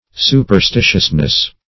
Su`per*sti"tious*ness, n.
superstitiousness.mp3